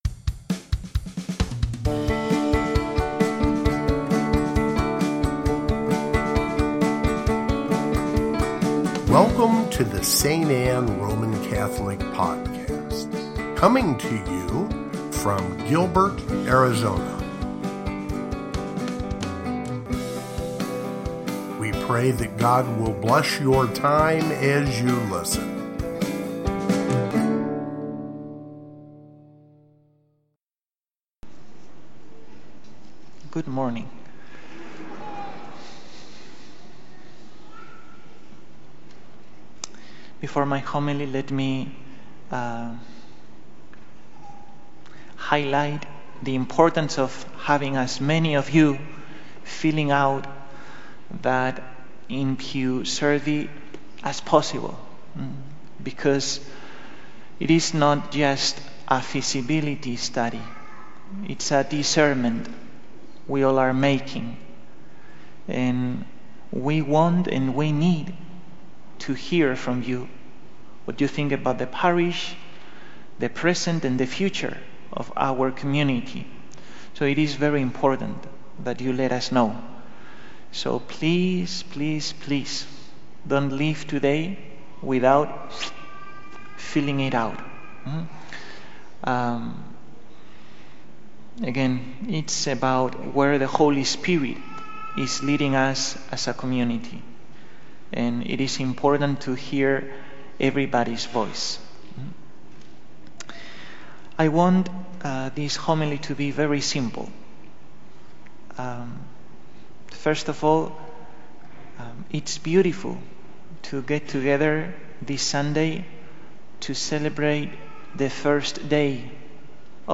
First Sunday of Advent (Homily) | St. Anne